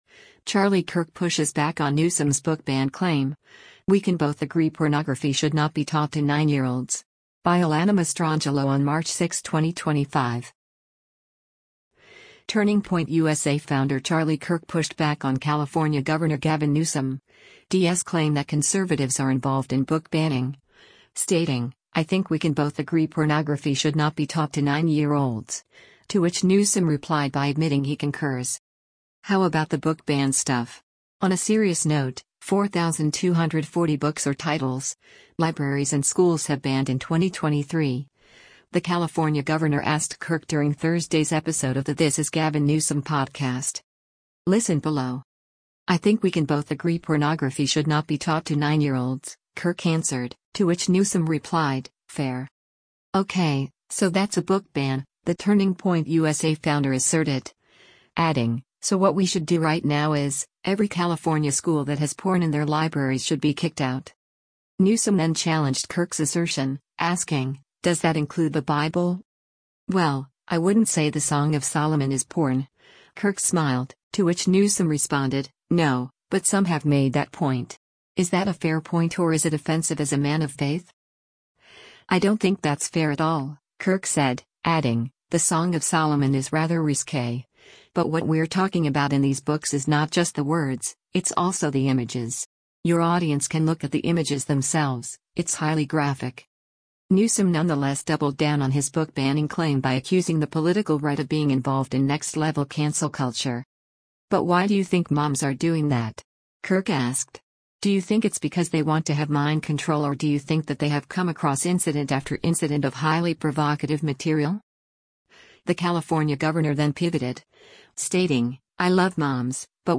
“How about the book ban stuff? On a serious note, 4,240 books or titles, libraries and schools have banned in 2023,” the California governor asked Kirk during Thursday’s episode of the This is Gavin Newsom podcast.